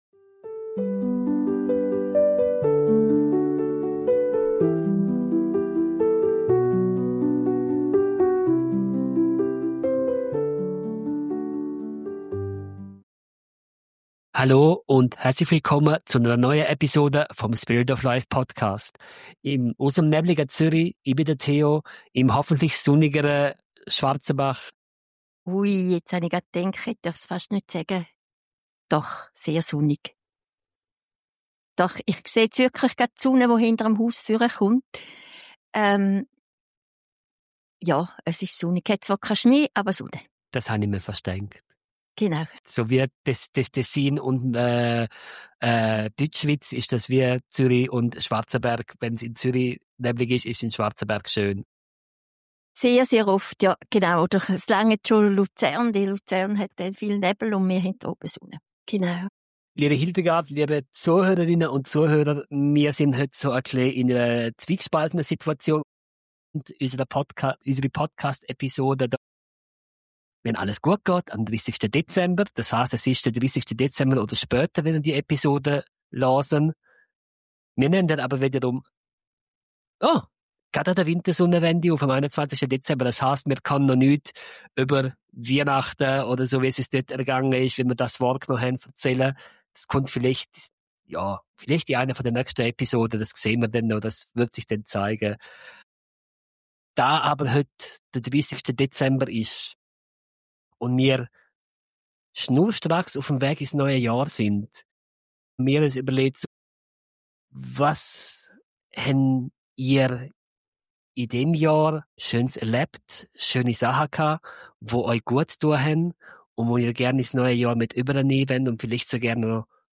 Eine ruhige, ermutigende Folge für den Übergang ins neue Jahr.